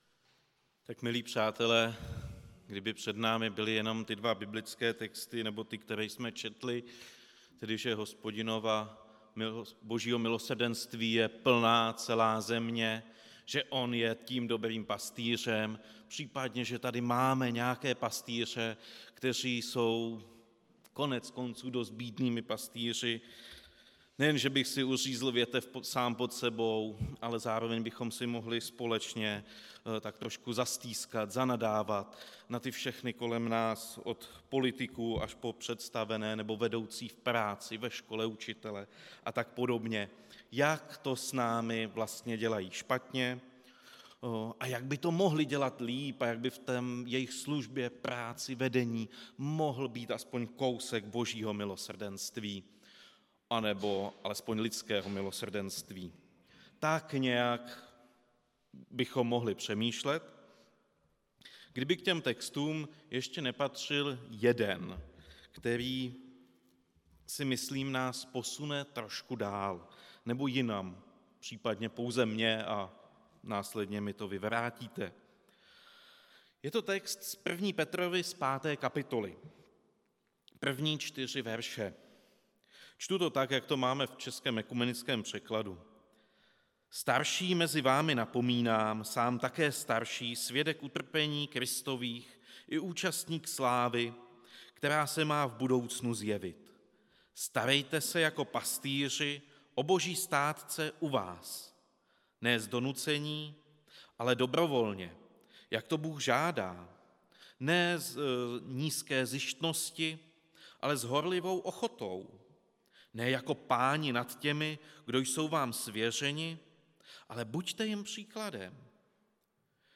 Kázání
Místo: Římská 43, Praha 2